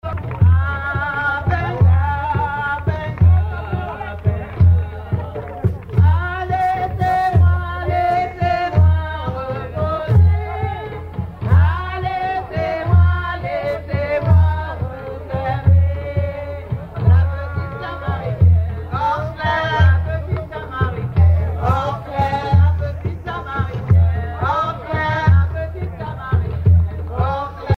groupe folklorique Buisson Ardent
danse : grajé (créole)
Pièce musicale inédite